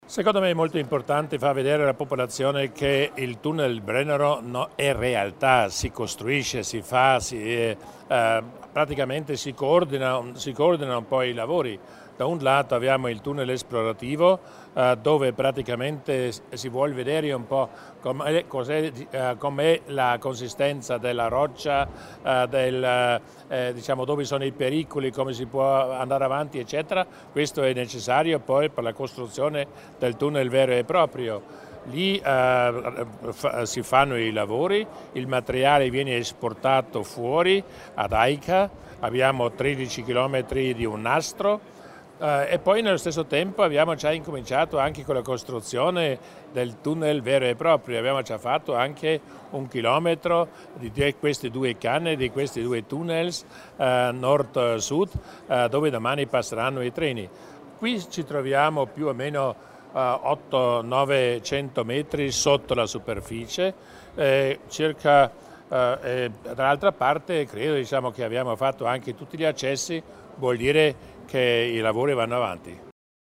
L'Assessore Widmann illustra le ricadute positive per l'economia locale